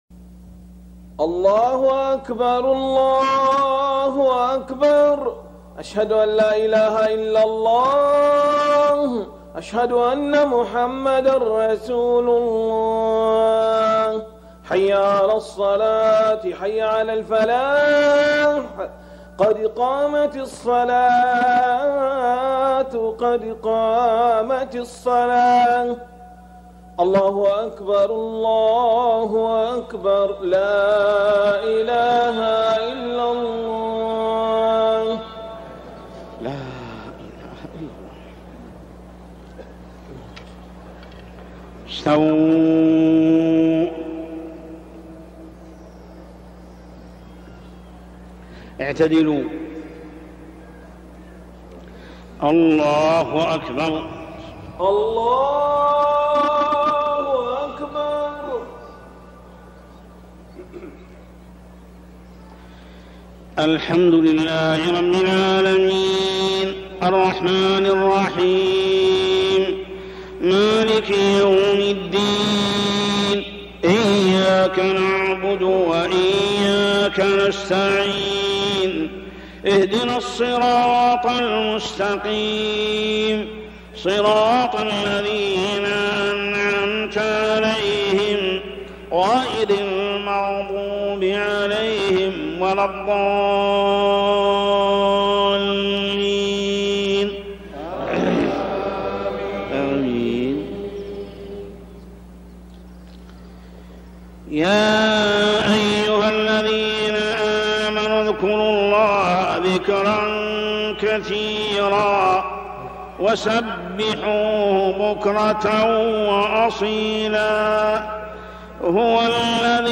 صلاة العشاء من المسجد الحرام 1421هـ من سورة الأحزاب 41 - 48 > 1421 🕋 > الفروض - تلاوات الحرمين